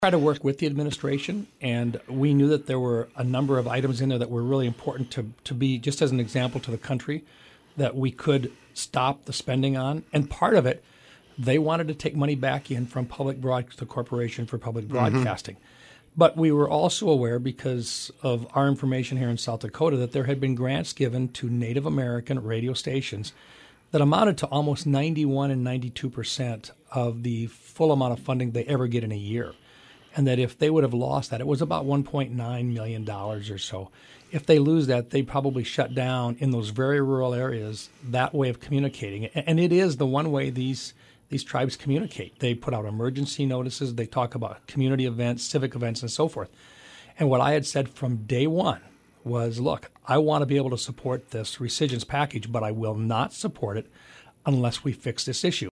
Recap of Senator Mike Rounds’ interview with Hub City Radio